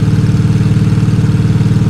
engine-loop.wav